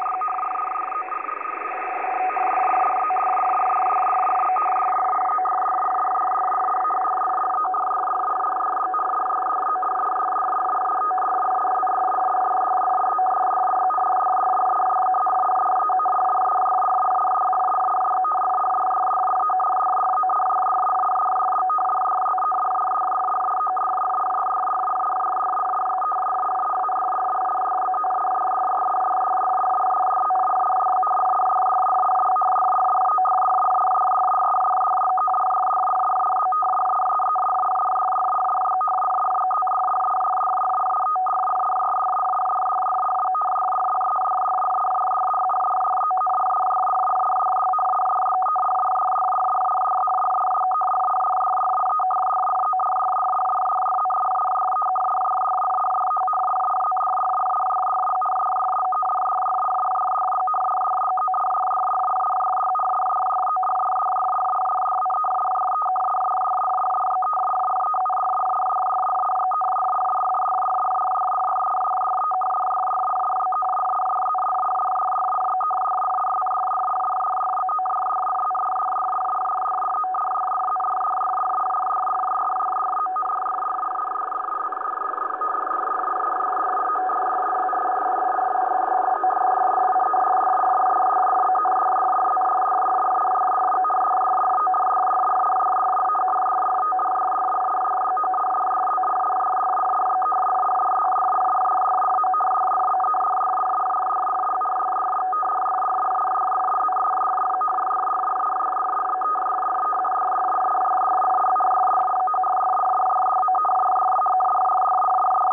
Начало » Записи » Радиоcигналы на опознание и анализ